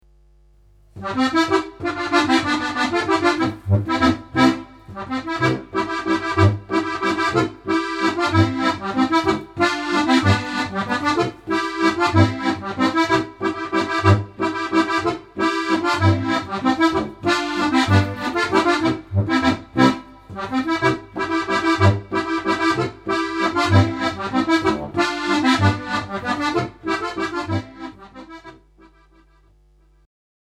Besetzung: Steirische Harmonika